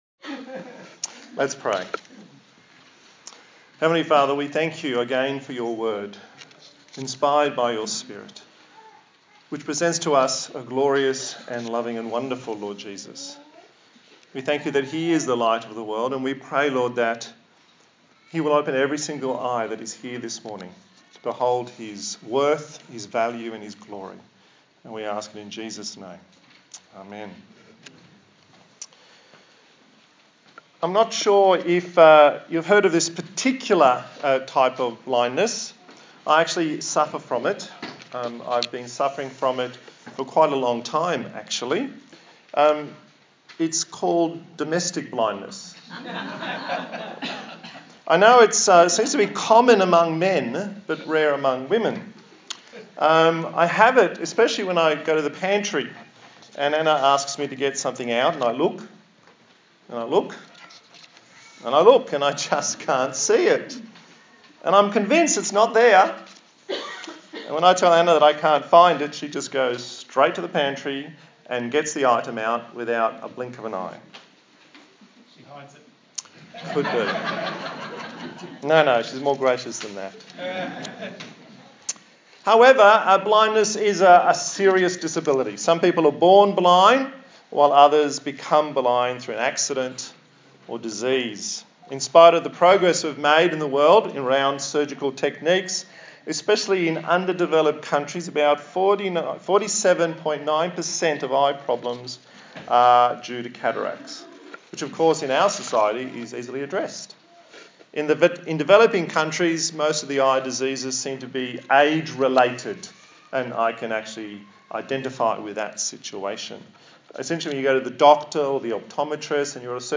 A sermon in the series on the book of John
Service Type: Sunday Morning